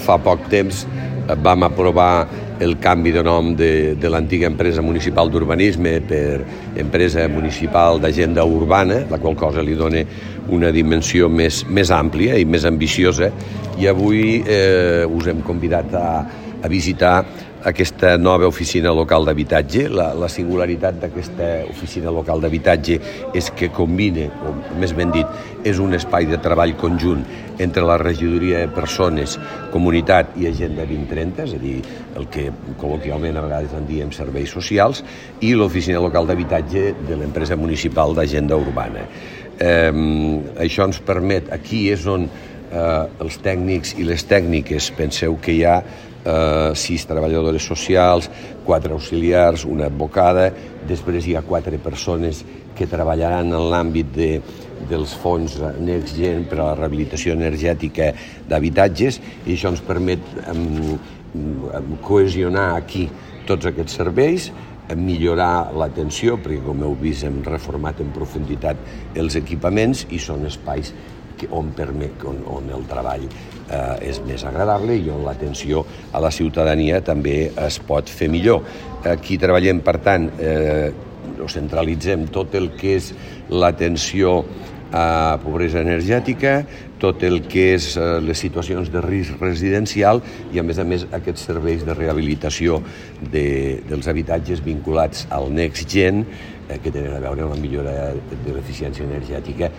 tall-de-veu-del-paer-en-cap-miquel-pueyo